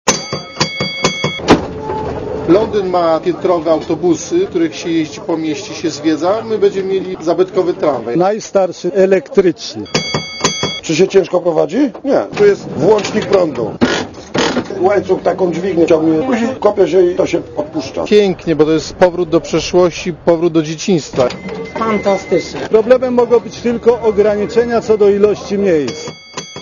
Ma prawie 80 lat, charakterystycznie piszczy na zakrętach i jest nową atrakcją turystyczną Gdańska.
Relacja reportera Radia Zet
tramwajgdansk.mp3